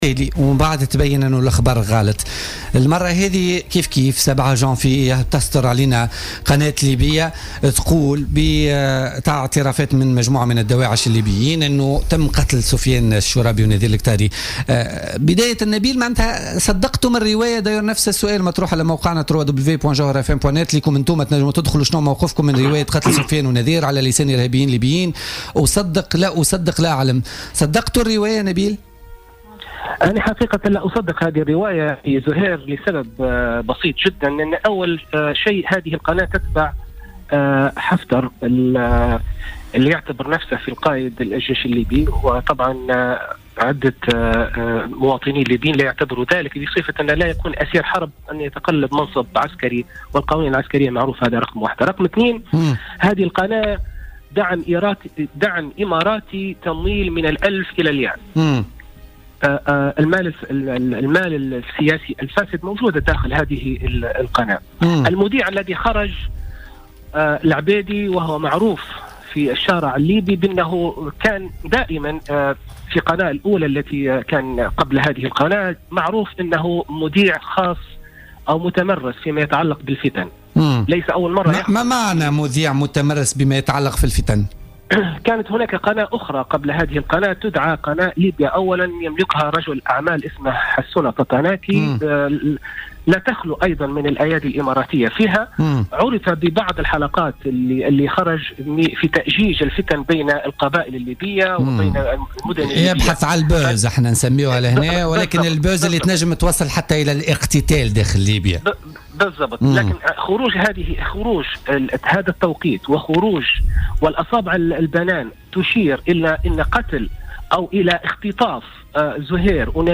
وشكك في اتصال هاتفي في "بوليتيكا" في مصداقية التقرير التلفزيوني الأخير حول اعترافات ارهابيين بقتل الاعلاميين التونسيين.